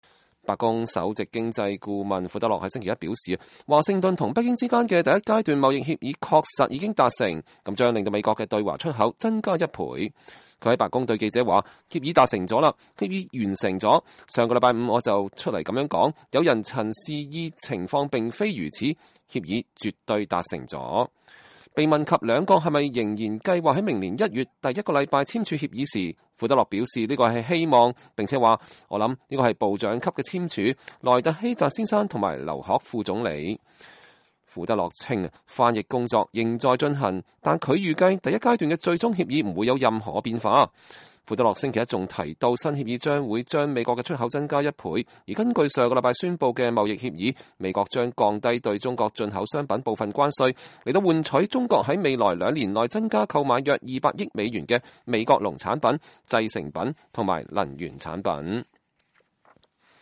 2019年12月16日，白宮首席經濟顧問庫德洛在白宮舉行的記者會上回答問題。(美聯社視頻截圖)